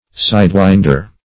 Sidewinder \Side"wind`er\, n.